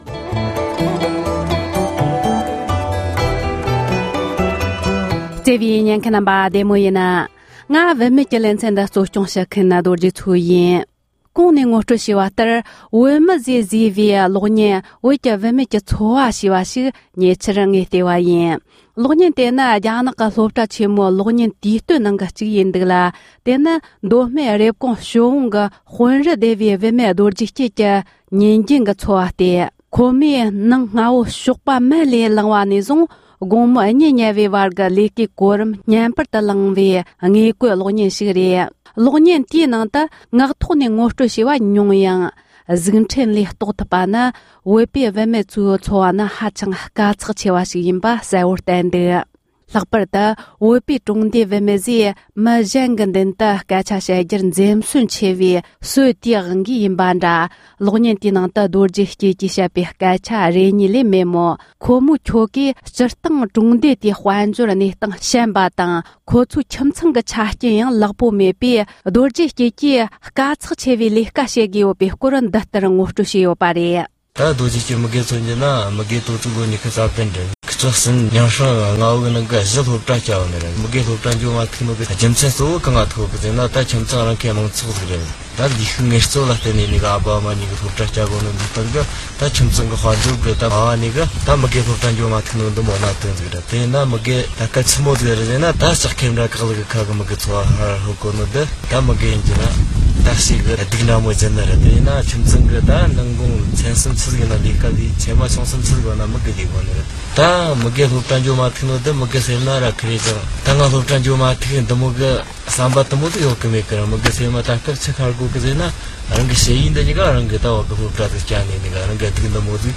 གླེང་མོལ་བྱས་ཡོད༎